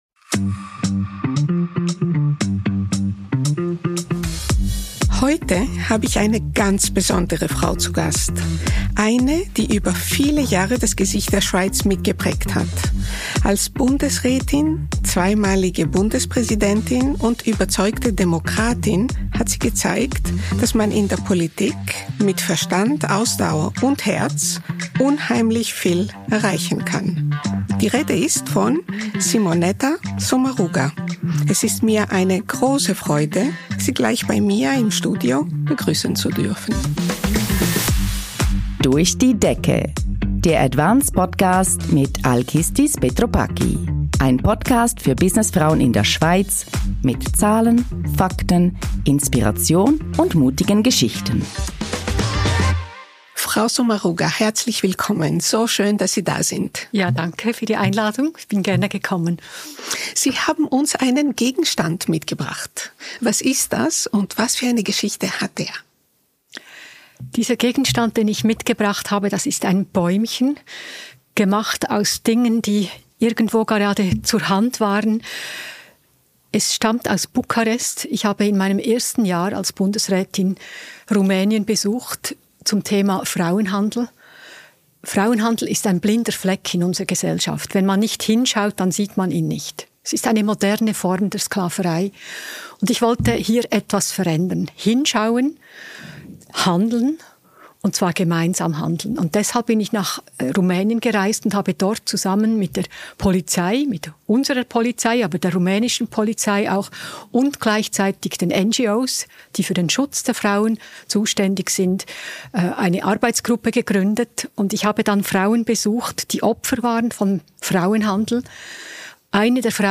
Ein Gespräch mit Klartext darüber, weshalb fairer Lohn mehr bedeutet als nur eine Zahl.